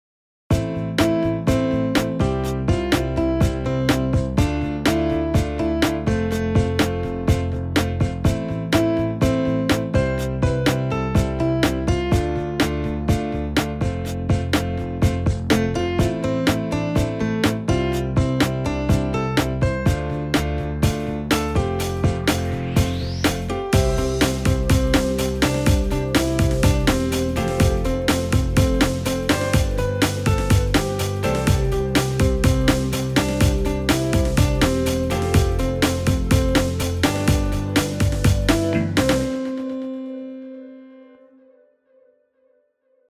in C guide for alto and soprano